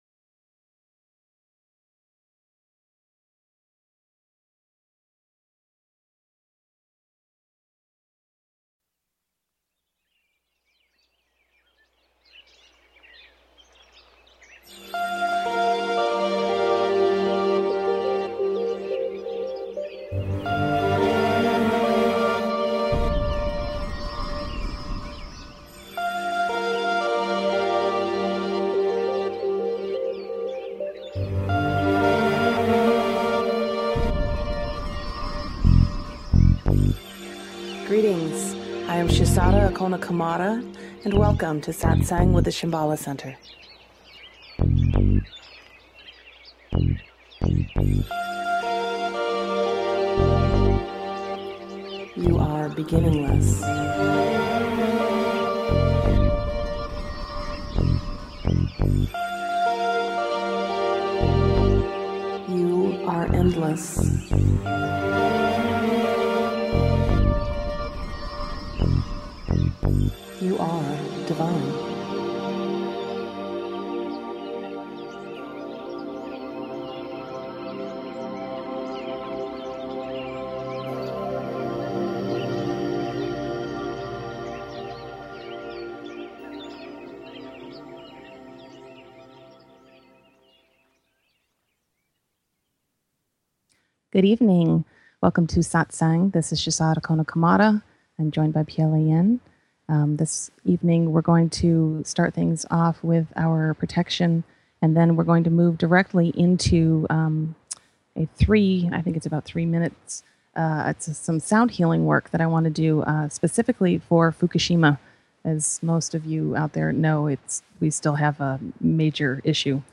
Talk Show Episode, Audio Podcast
The guide facilitates the session by holding the energy for the group, providing teachings, answering questions and facilitating meditations.